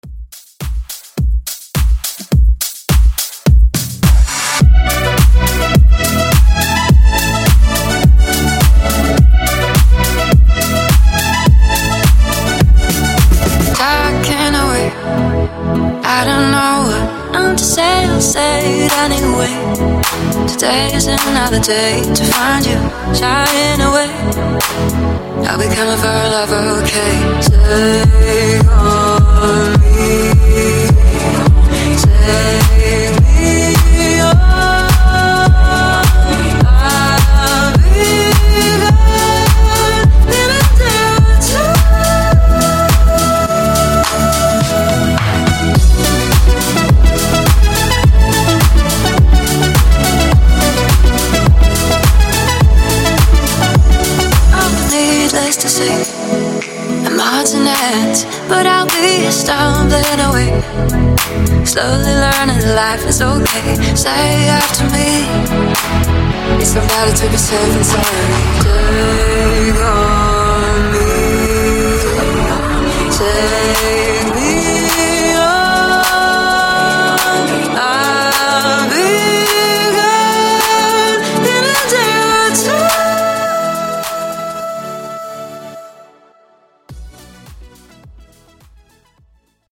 Genre: 90's
Clean BPM: 112 Time